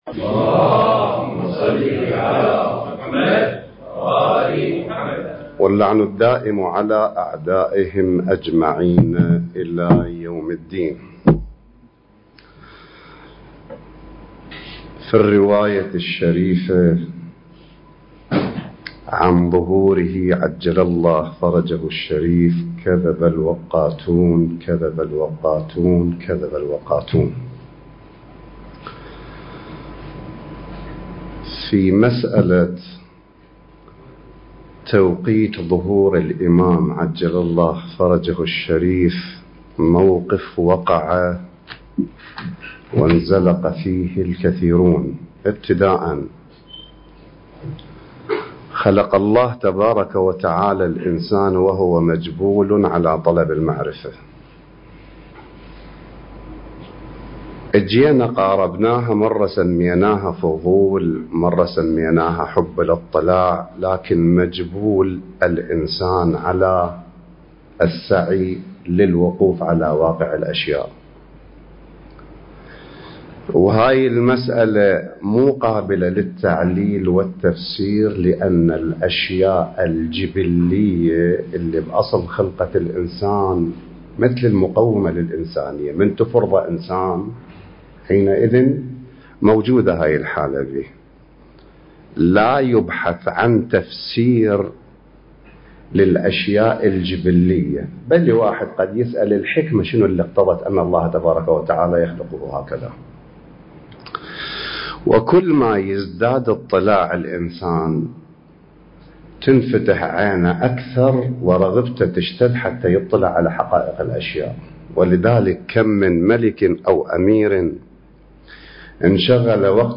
المكان: مركز خاتم الأوصياء (عجّل الله فرجه)/ جامع الرسول (صلّى الله عليه وآله) / بغداد